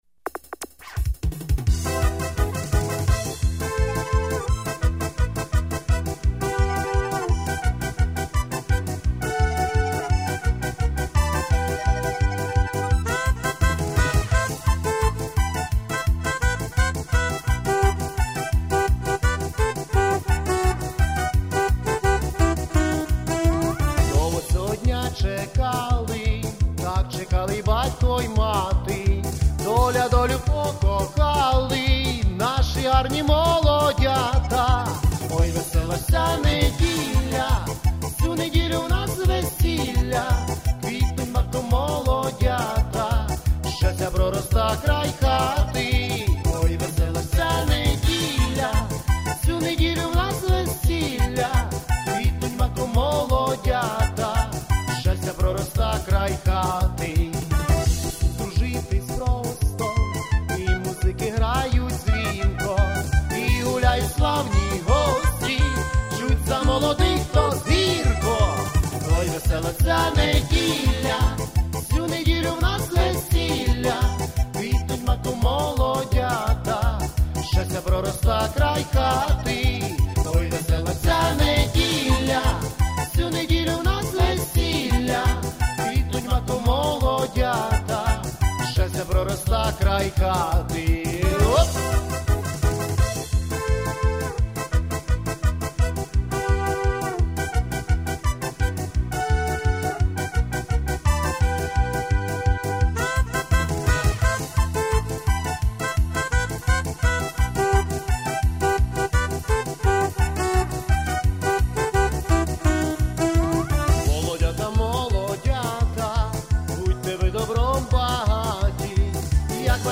ОЙ, ВЕСЕЛА Ж ЦЯ НЕДІЛЯ (весільна пісня)
Рубрика: Поезія, Авторська пісня